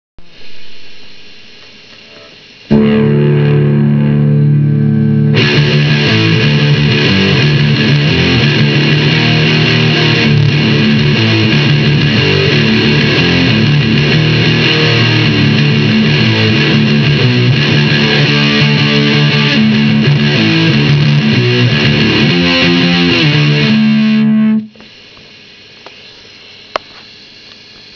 Headless strat style made from scratch all metal parts are titanium all joints helicoil/stainless machine screws
Metallic black nitro finish Wilkinson HSS pickups .Now modified with an EMG 81 active pickup